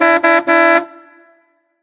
Klaxon de base